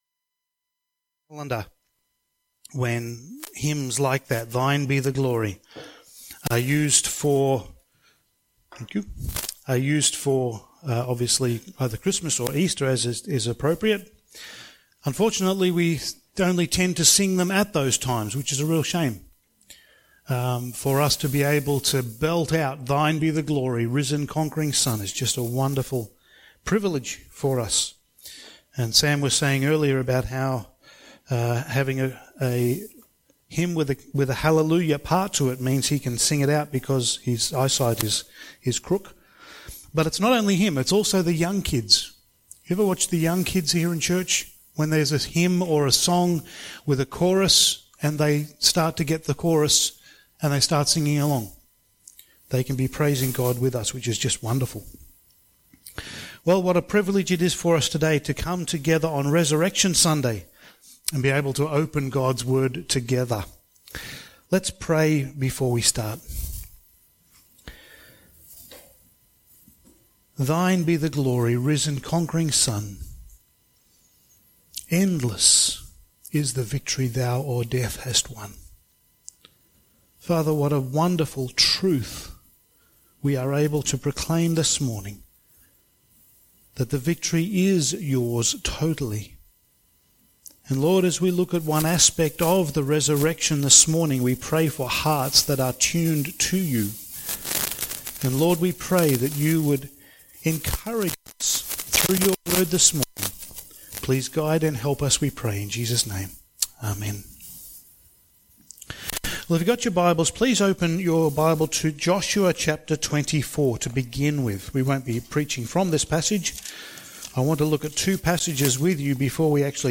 Topical Sermon Service Type: Sunday Morning